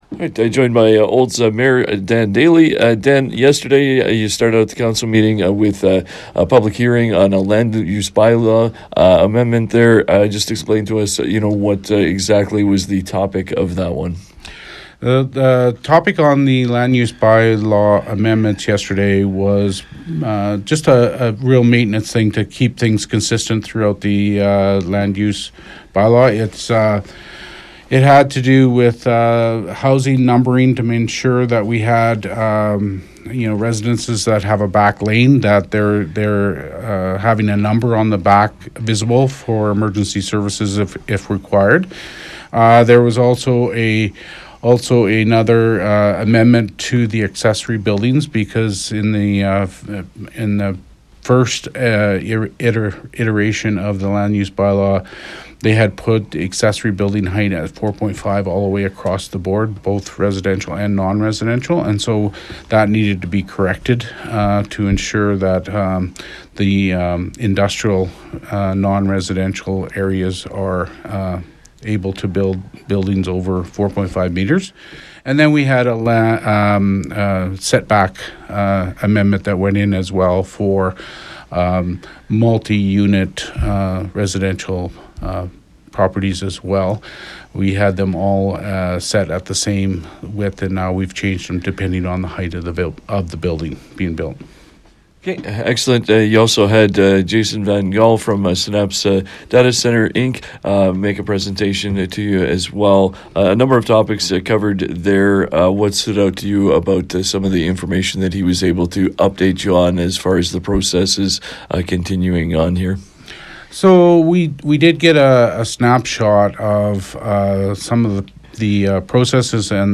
Listen to 96.5 The Ranch’s conversation with Mayor Dan Daley from February 24th.